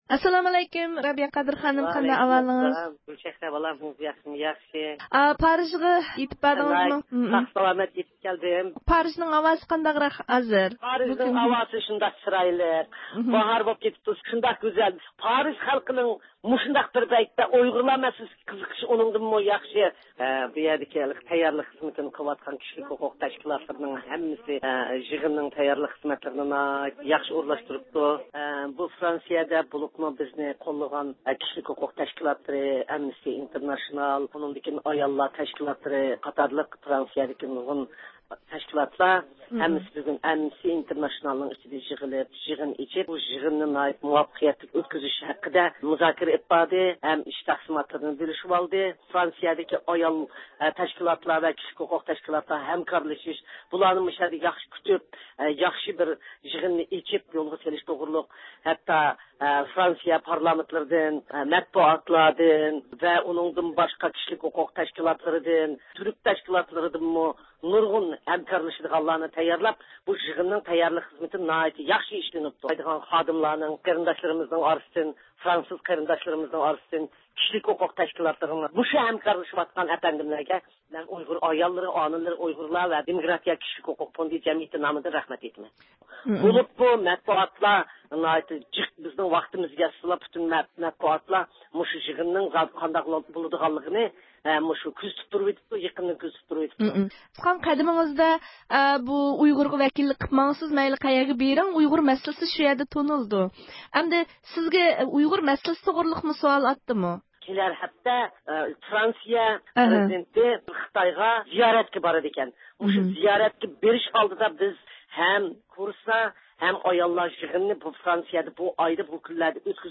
پارىژدىن مۇخبىرىمىزنىڭ تېلېفون زىيارىتىنى قوبۇل قىلغان رابىيە قادىر خانىم، خىتاي ھۆكۈمىتىنىڭ كۆپ خىل توسقۇنلۇق قىلىش ھەرىكەتلىرىنىڭ ھېچقانداق ئۈنۈم بەرمىگەنلىكى، ئەكسىچە پائالىيەتلىرىنىڭ ئىنتايىن ئوڭۇشلۇق باشلانغانلىقىنى بىلدۈردى ۋە ئۇيغۇر داۋاسىنىڭ فرانسىيىدە تېخىمۇ زور تەسىر قوزغايدىغانلىقىغا بولغان ئىشەنچىنى ئىپادىلىدى.
ئاۋاز ئۇلىنىشى ئارقىلىق، مۇخبىرىمىزنىڭ رابىيە قادىر خانىم بىلەن قىلغان سۆھبىتىنى ئاڭلىغايسىلەر.